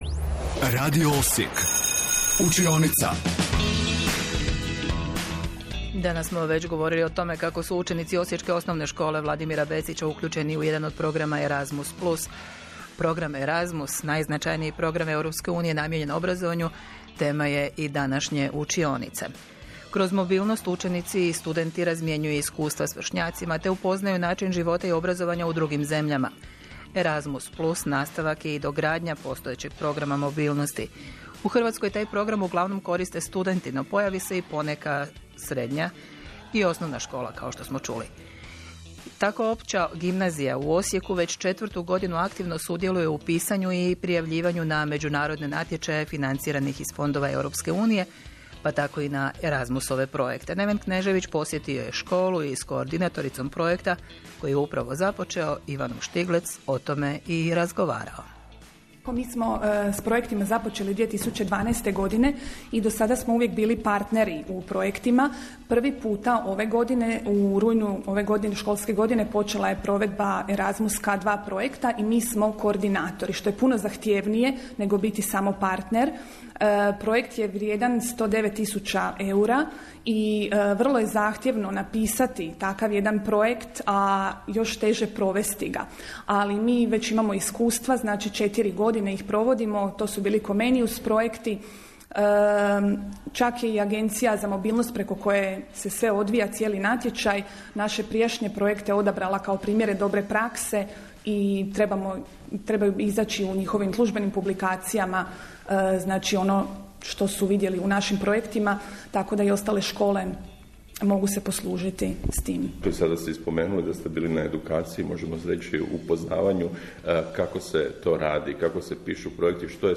Hrvatski radio,Radio Osijek posjetio je našu školu, te su snimili reportau o našim Erasmus+ projektima.